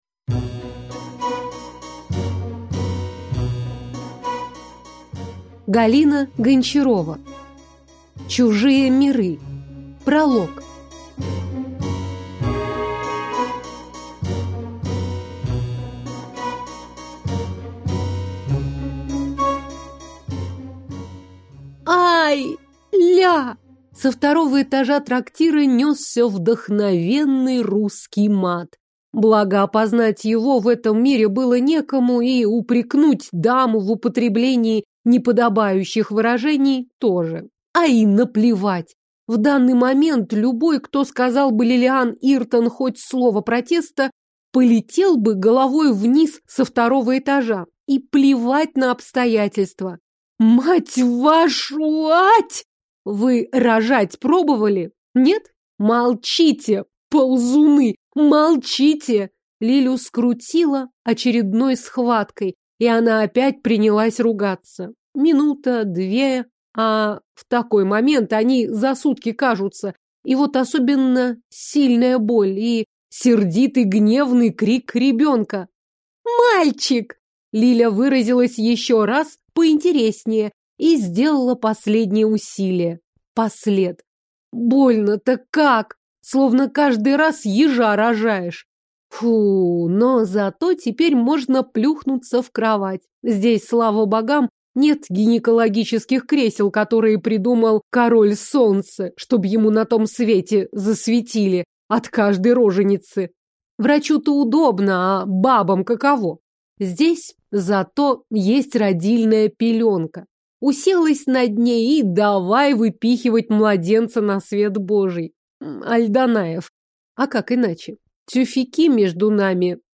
Аудиокнига Средневековая история. Чужие миры | Библиотека аудиокниг